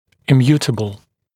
[ɪ’mjuːtəbl][и’мйу:тэбл]неизменный, постоянный, фиксированный